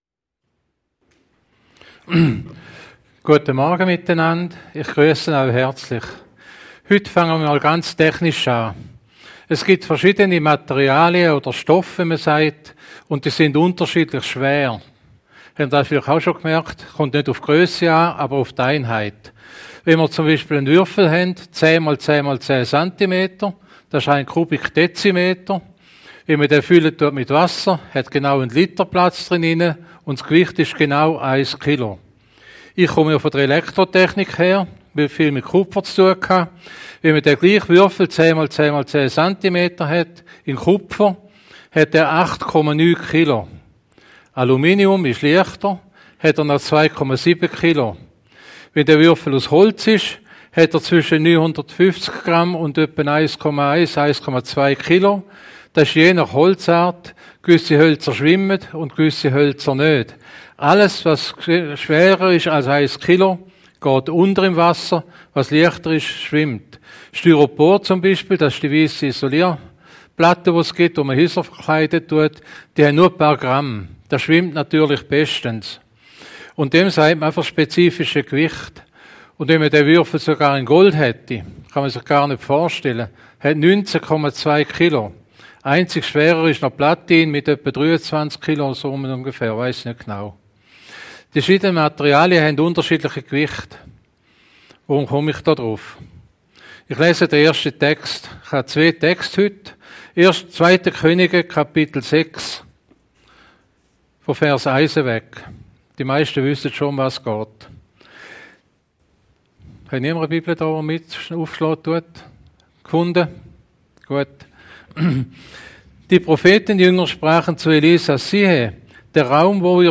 Predigt vom 8.10.2023 - Christengemeinde Offenes Haus
Passage: johannes 2:1-12 Dienstart: Sonntagmorgengottesdienst « Prophetieabend Was ist der Glaube